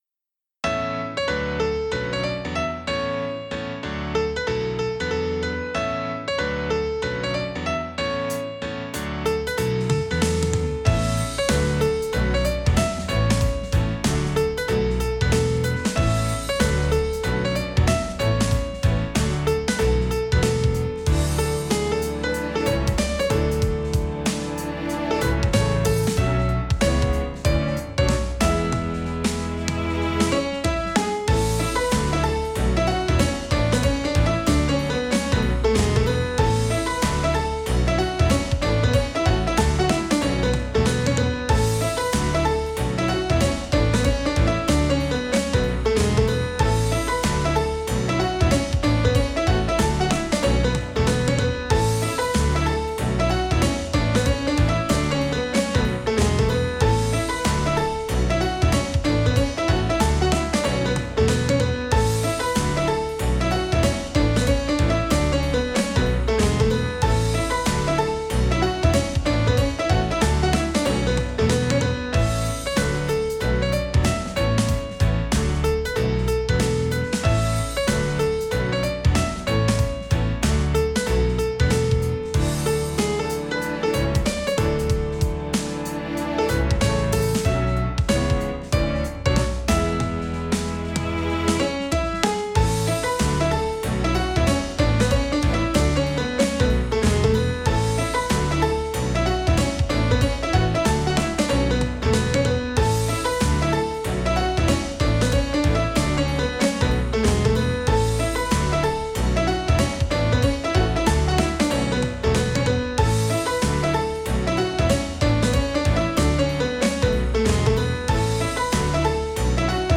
ほのぼのした雰囲気のコミカルな日常系BGMです。